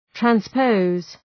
Προφορά
{træns’pəʋz}